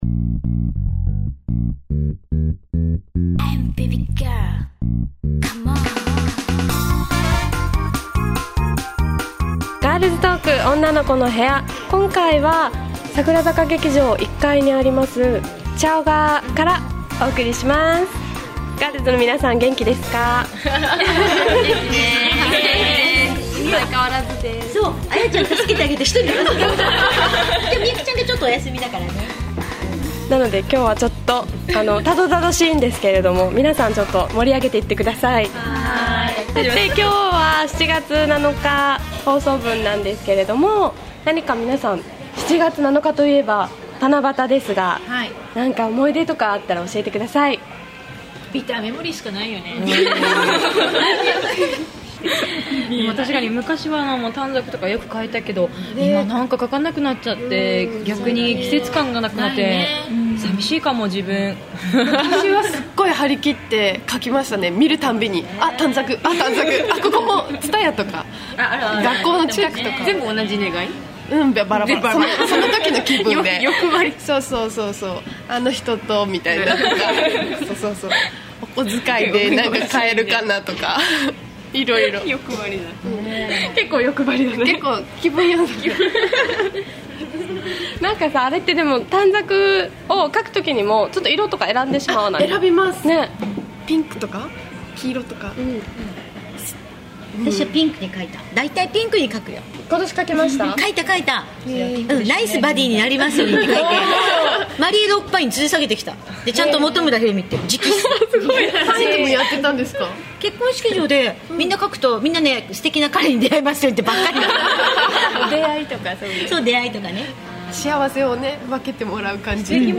素敵な歌も披露してくれました